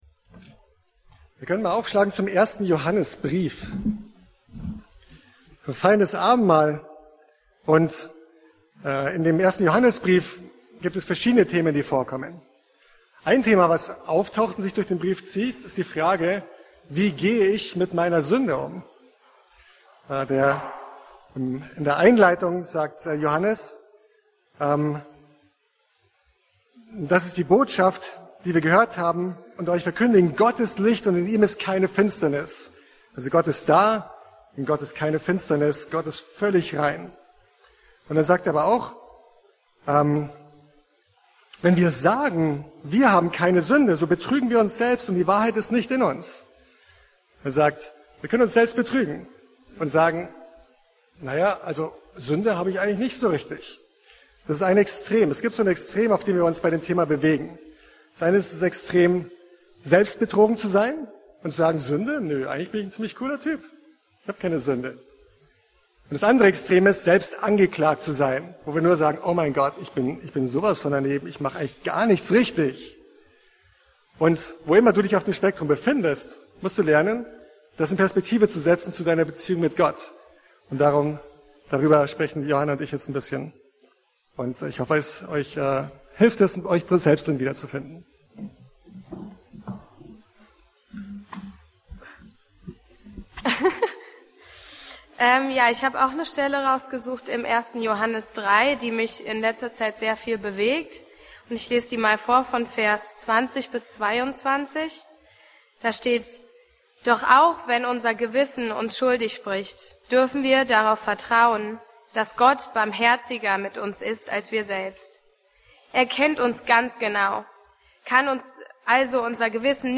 E-Mail Details Predigtserie: Abendmahl Datum